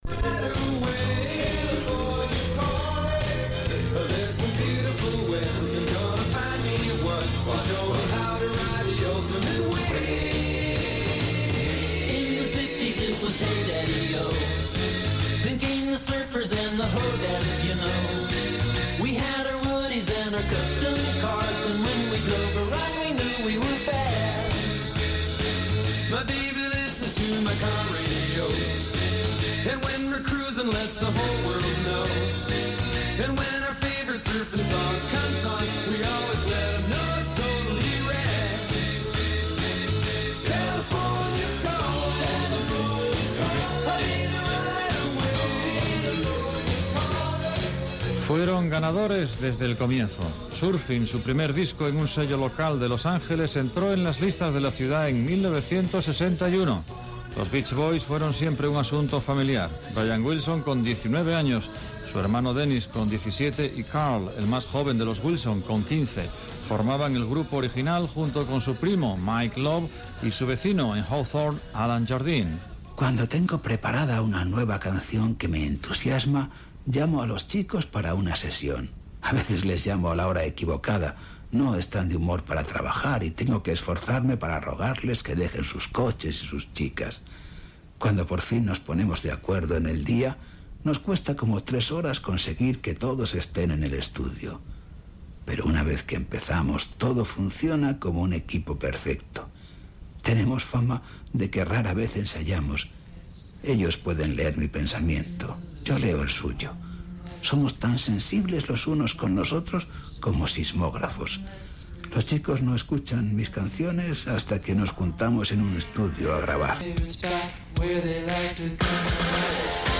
Tema musical, dades del grup The Beach Boys, temes musicals i més informació històrica del grup
Musical